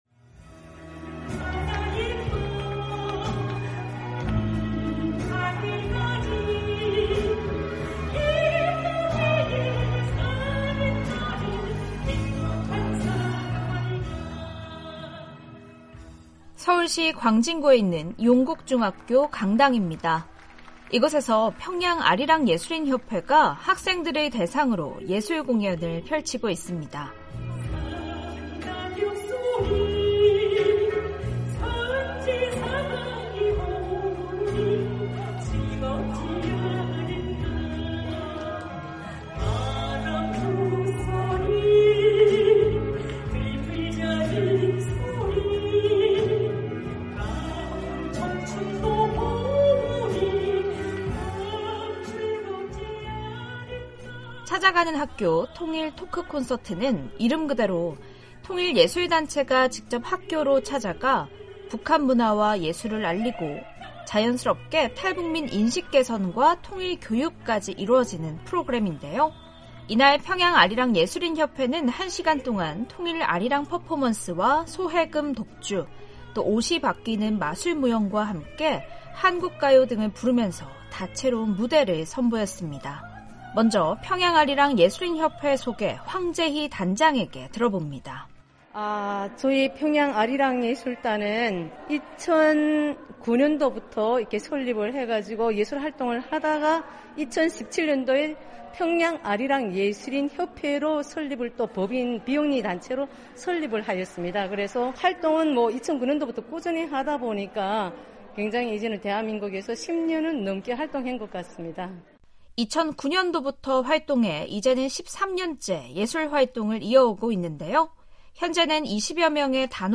탈북민들의 다양한 삶의 이야기를 전해드리는 ‘탈북민의 세상 보기’, 오늘은 ‘찾아가는 학교 통일 토크콘서트’ 현장으로 안내해드립니다.